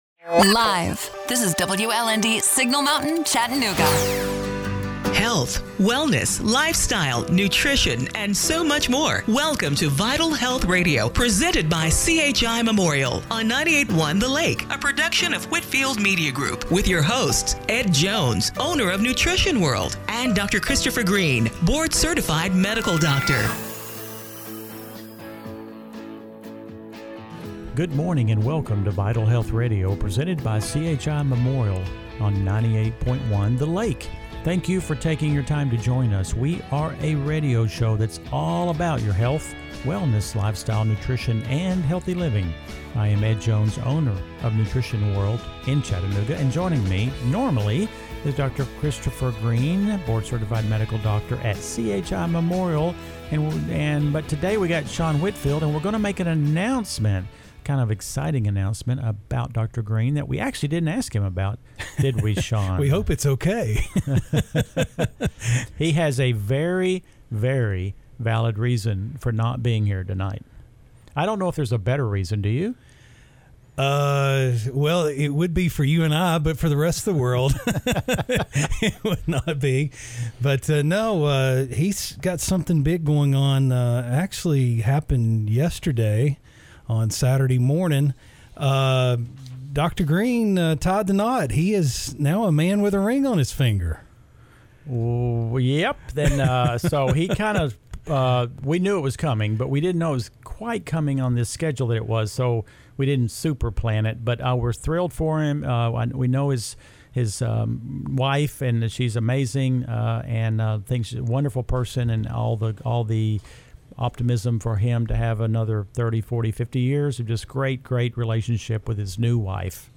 COVID-19 Updates & Discussion